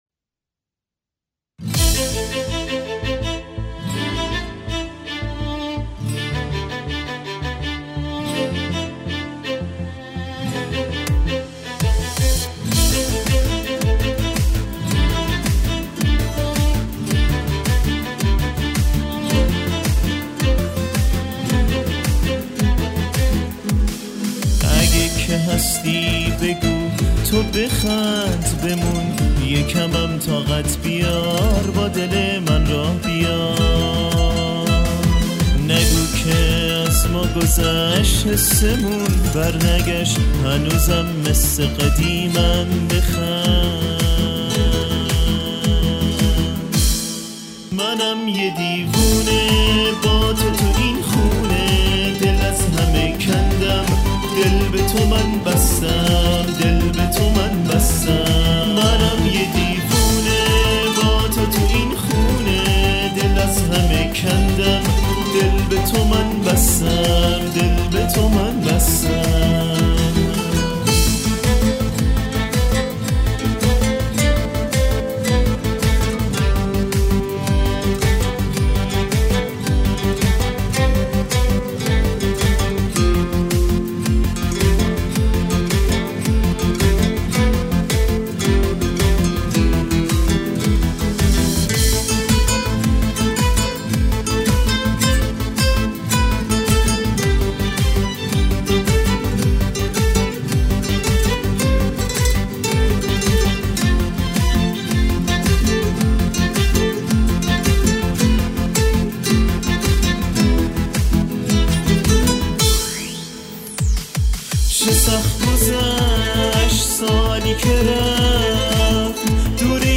میکس و مستر افتضاح صدات خیلی خیابونی و گوش خراش شده بود